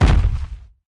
footstep_large.ogg